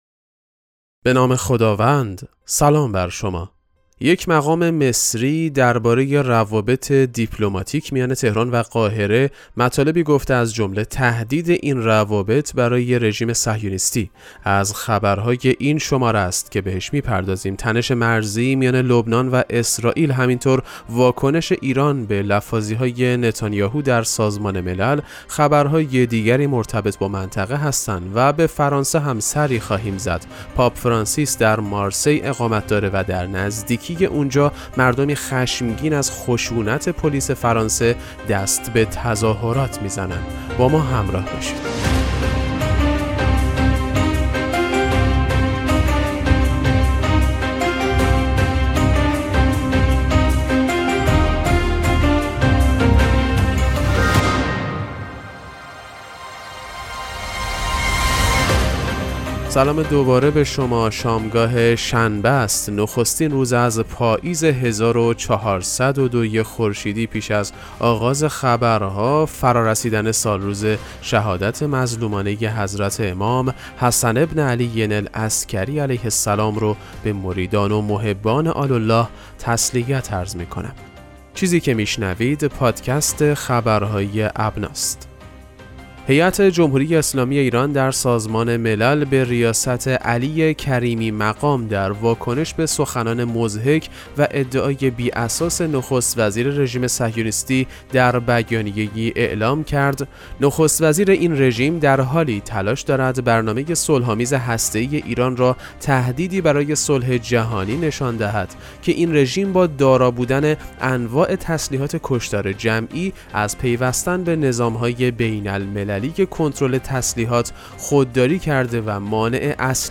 پادکست مهم‌ترین اخبار ابنا فارسی ــ یکم مهر 1402